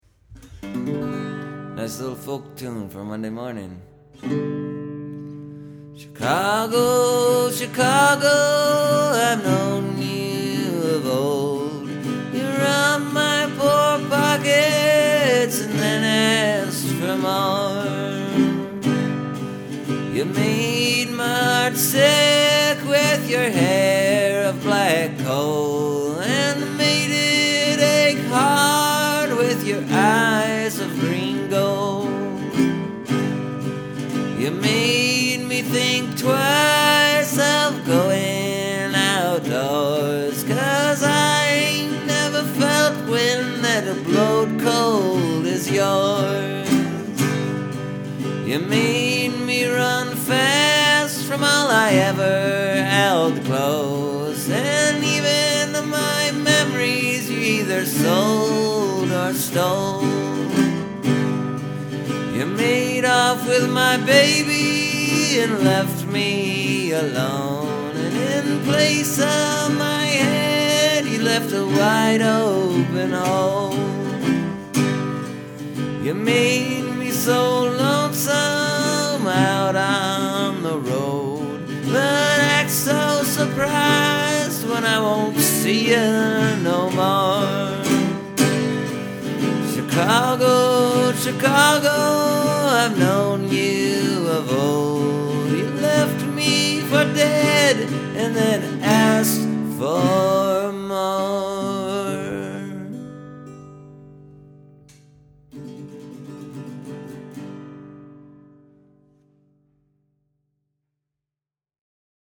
Well, I haven’t written a straight-up folk song for a few weeks, so this one kinda popped out easy last night. Had this idea floatin around up in my head, over the weekend, to do a tune about the vagrancy law in Chicago that says if you’ve got less than one dollar in your pocket they can charge you and lock you up and throw out the key.